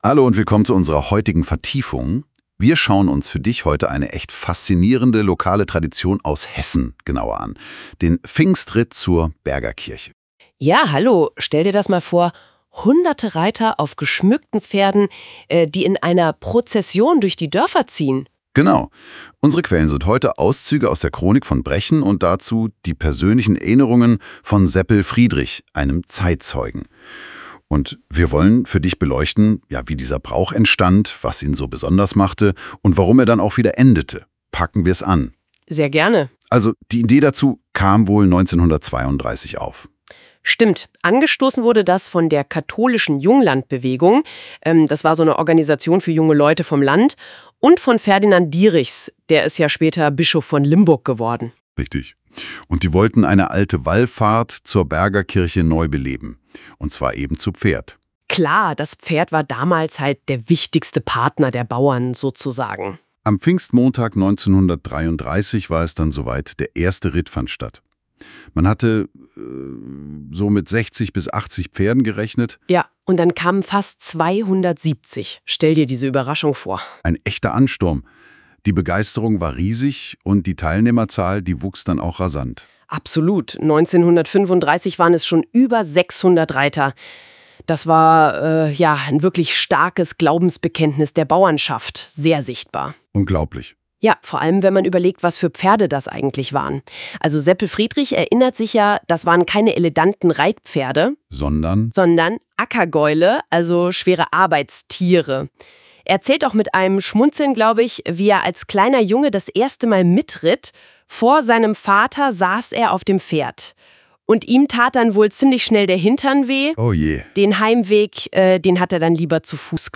KI-Podcast | Eisenbach - Einst und Jetzt